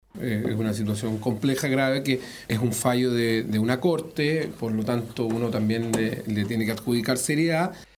El diputado UDI y miembro de la Comisión de Gobierno Interior, Celso Morales, así lo expuso.